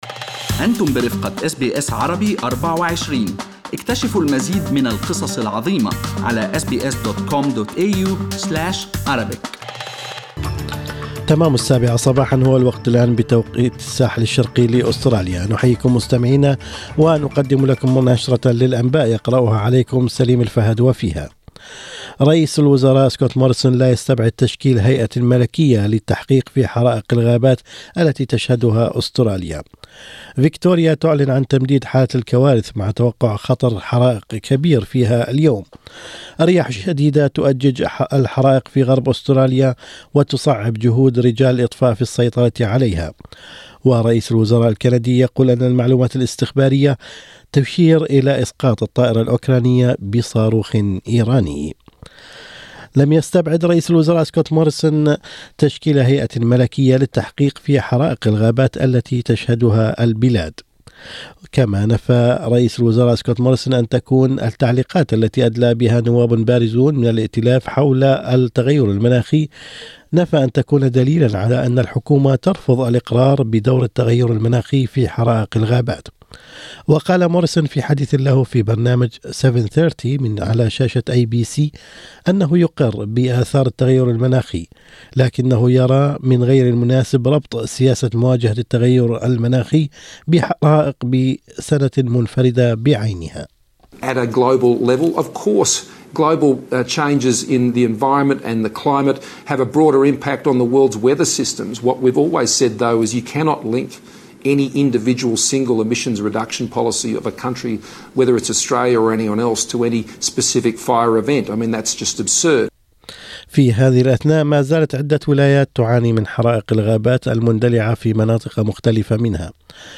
أخبار الصباح: الرياح الشديدة والحرارة تزيدان من خطر الحرائق بقوة في عدة ولايات اليوم